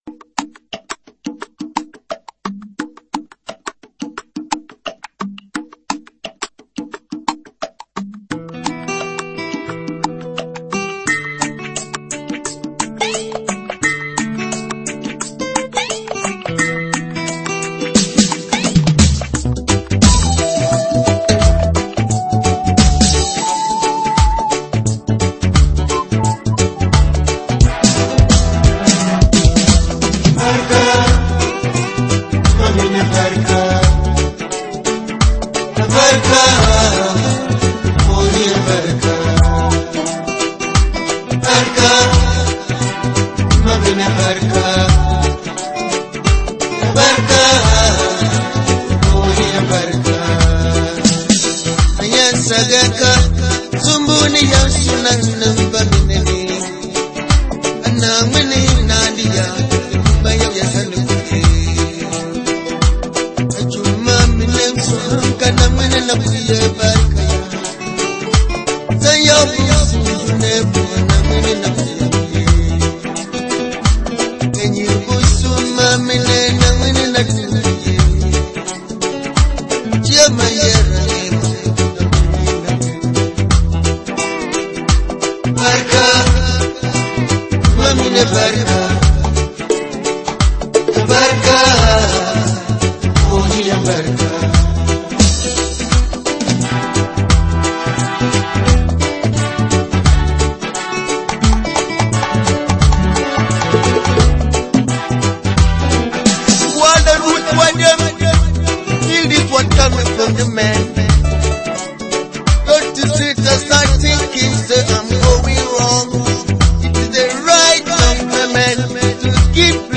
Dagaari/Waali Music